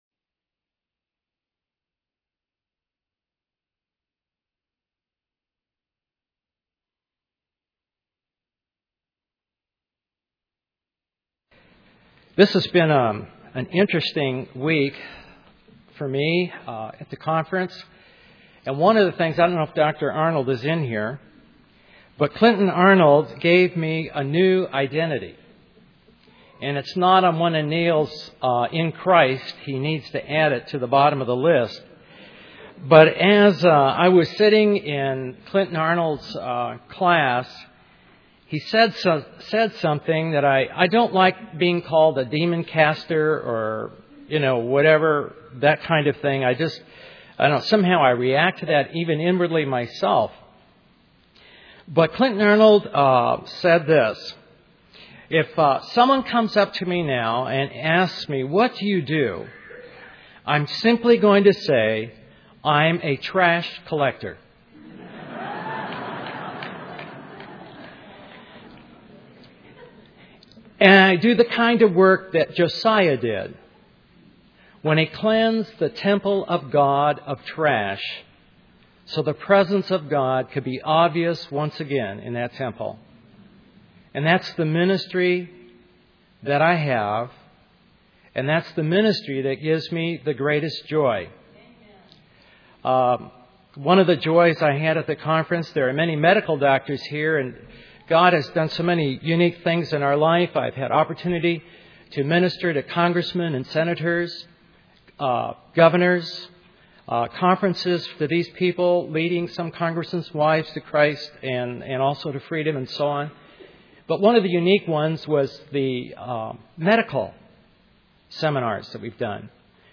In this sermon, the speaker shares a powerful testimony of a 14-year-old boy who was set free from anorexia through the power of God.